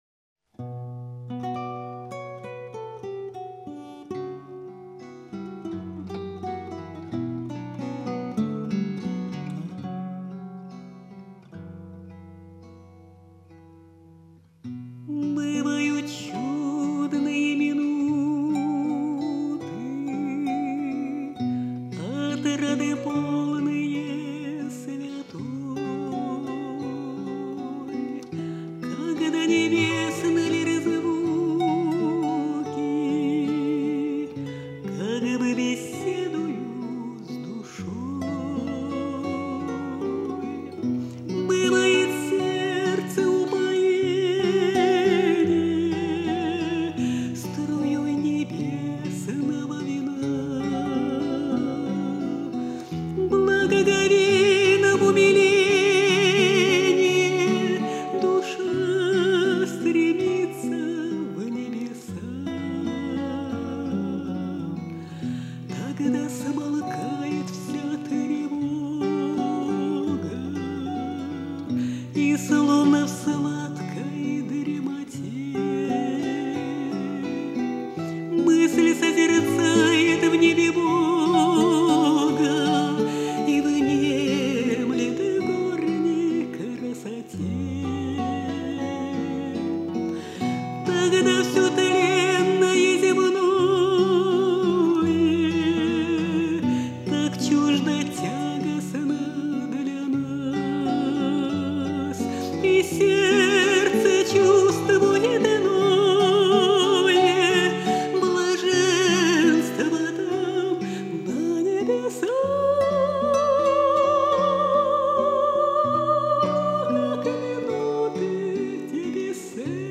Духовная музыка
Она обладает глубоким лирико - драматическим меццо-сопрано.